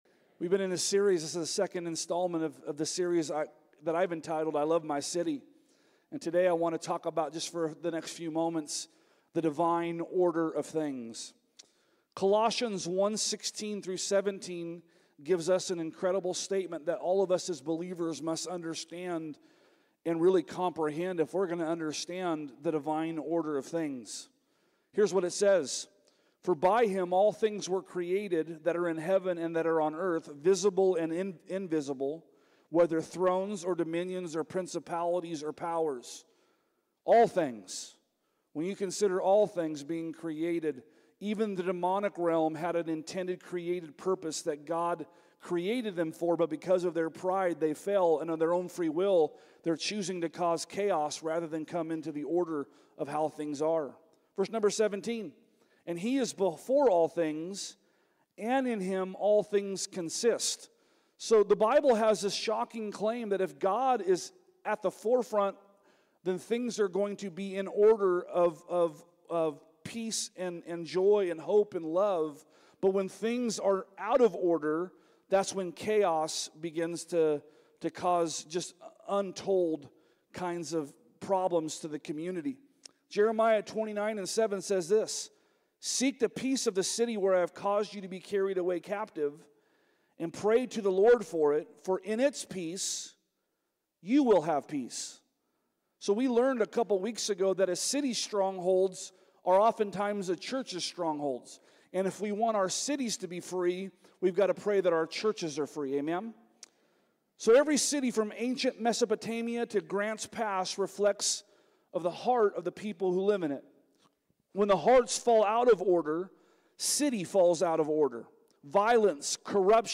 Sermons | Parkway Christian Center